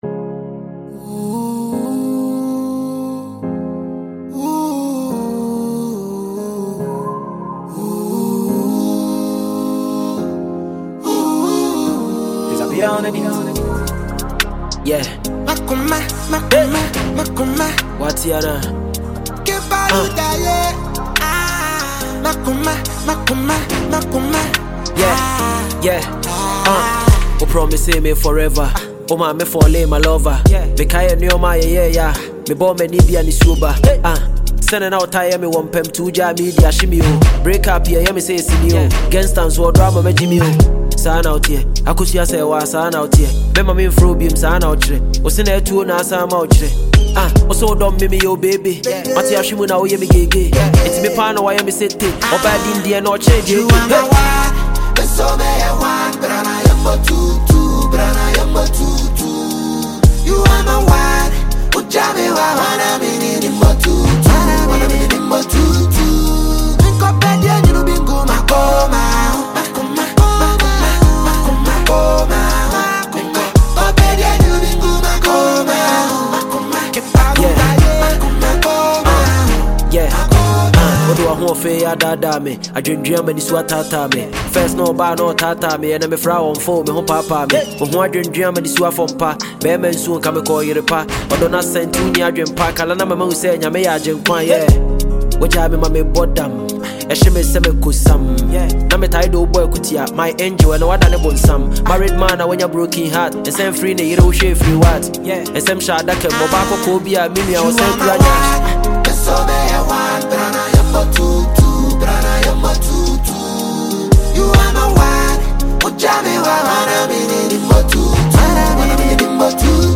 GHANA SONG NEW RELEASES
heartfelt tune
melodic flow adds warmth and authenticity to the song.